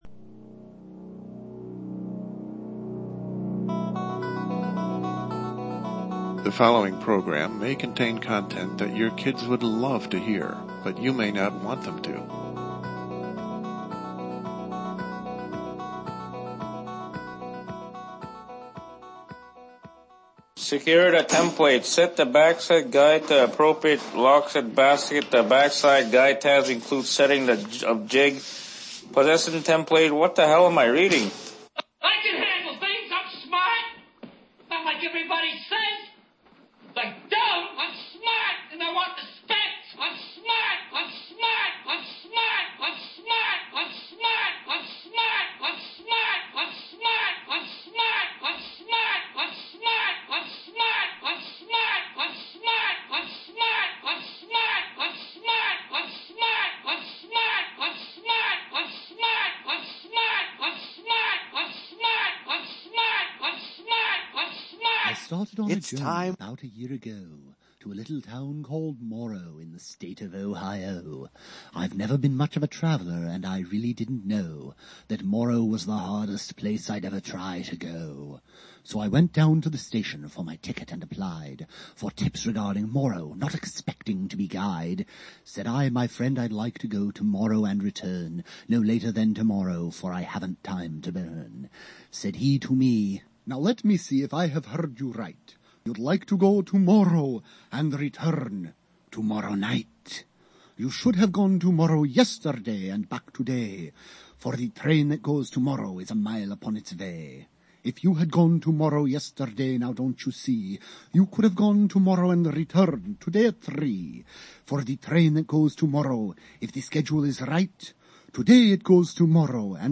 Our host “fixes” the show with a magic tool and many other things that no one has planned at the moment this blog is being posted surface — on the Internet’s first all-talk broadcast, dating back so many years there was no Internet.
interview